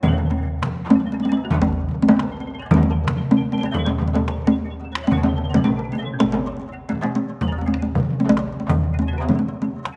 [from non-commercial, live recordings]